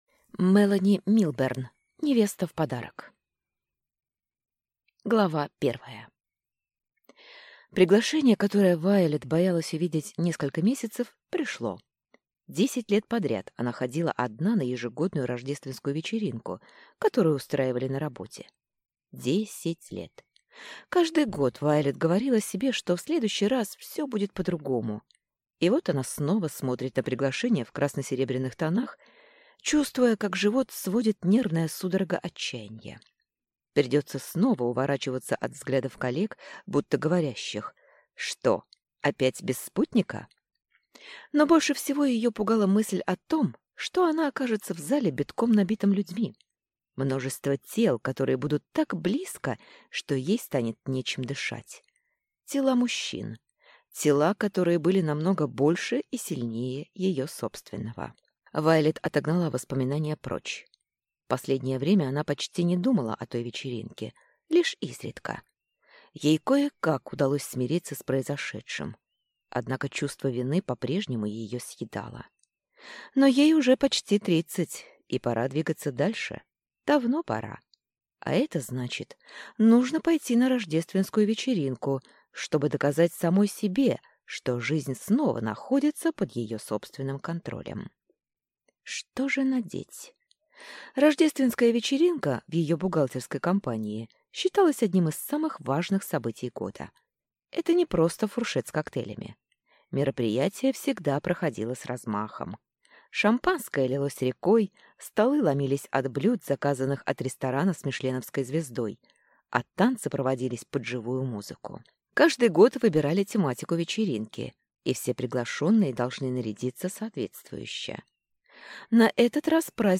Аудиокнига Невеста в подарок | Библиотека аудиокниг